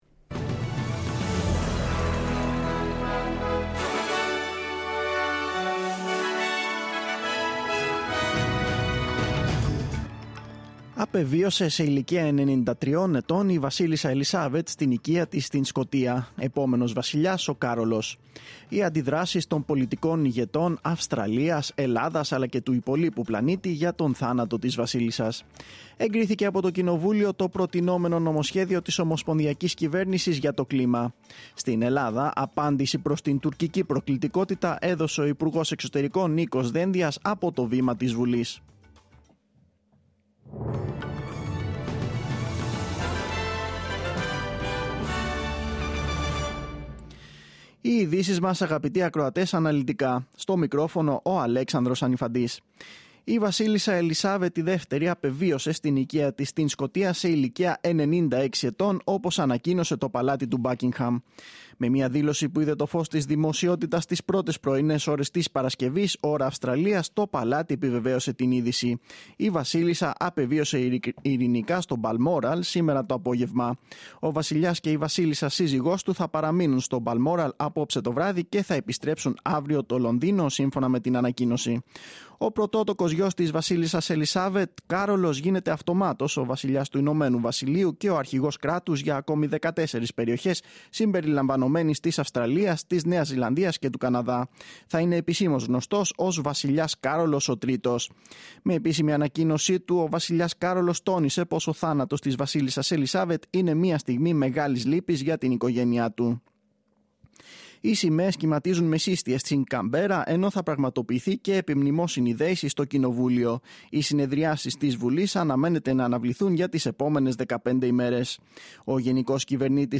Δελτίο Ειδήσεων: Παρασκευή 9-9-2022
News in Greek. Source: SBS / SBS Radio